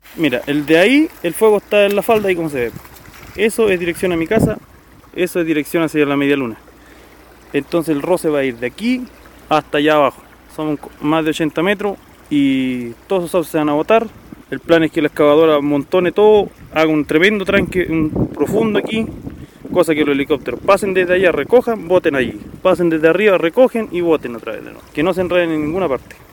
Vecinos de Lonquimay piden apoyo de maquinaria